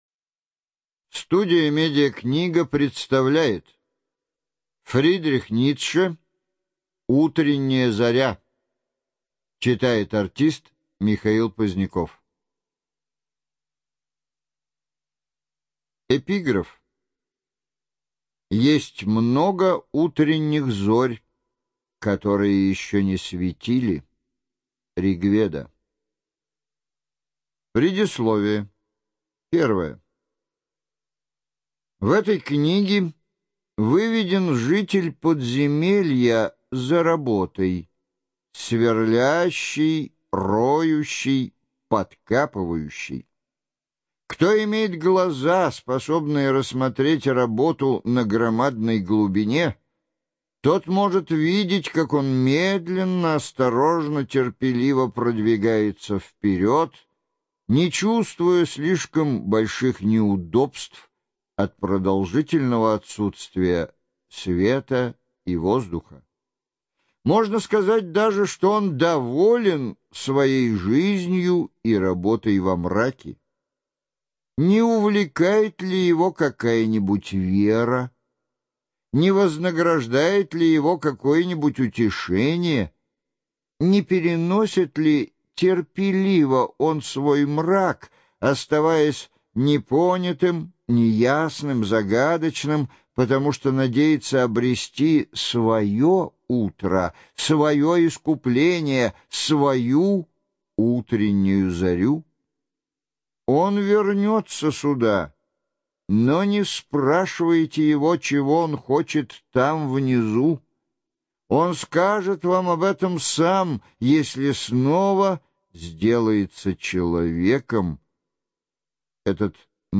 Студия звукозаписиМедиакнига